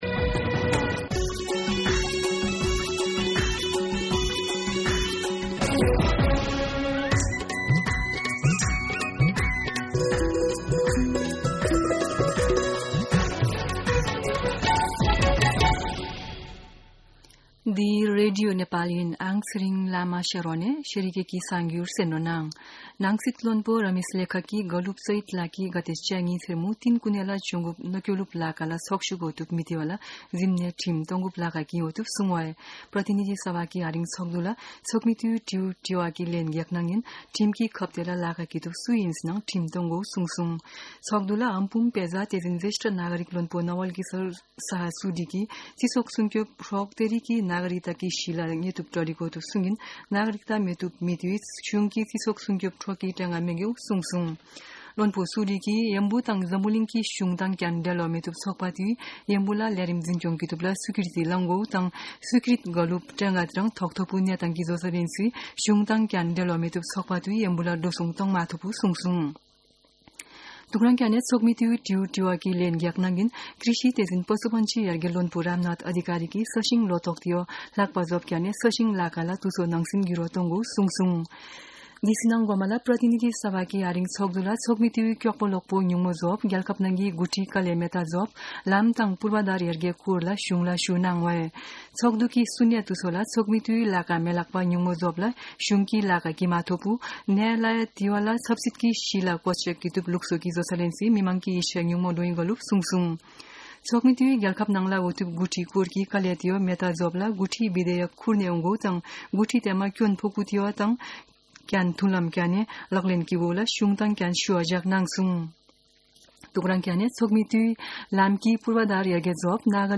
शेर्पा भाषाको समाचार : ५ जेठ , २०८२